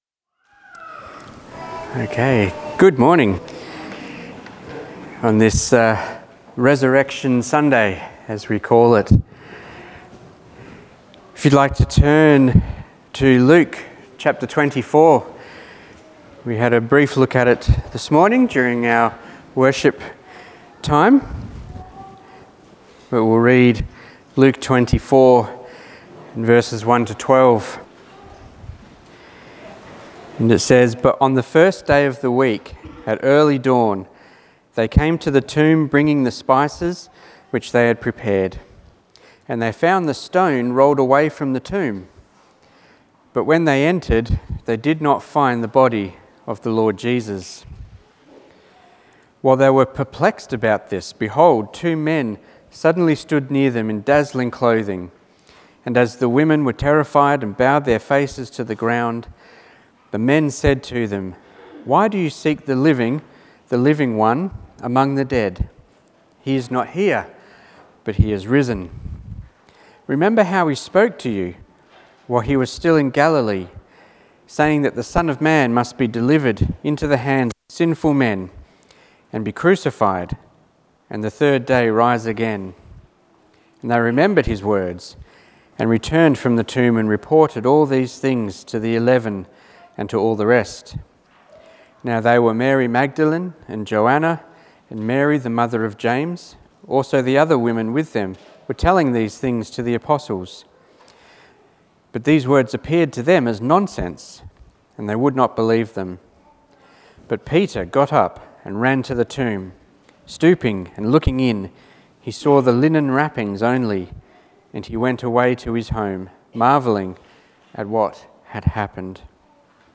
Passage: Luke 24:1-12 Service Type: Family Service